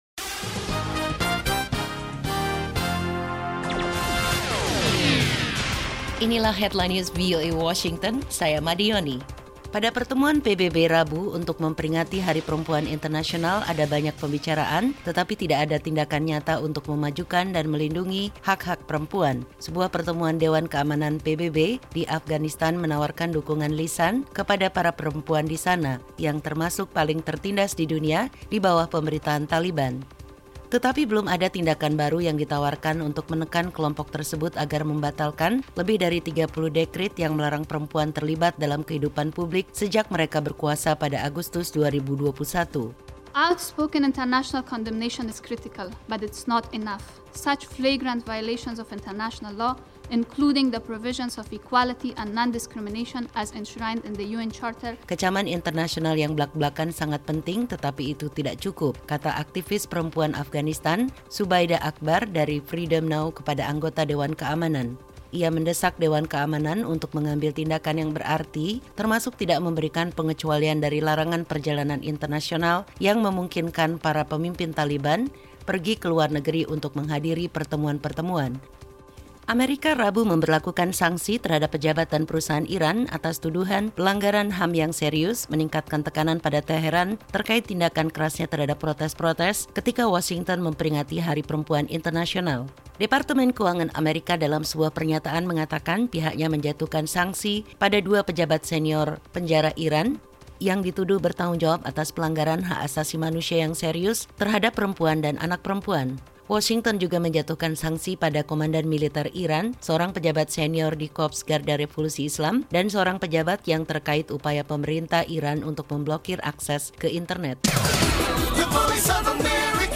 VOA Headline News